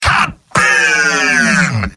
Robot-filtered lines from MvM. This is an audio clip from the game Team Fortress 2 .